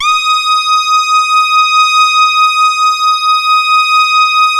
87-TARKUS D#.wav